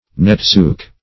netsuke - definition of netsuke - synonyms, pronunciation, spelling from Free Dictionary
Netsuke \Net"su*ke\, n. [Jap.]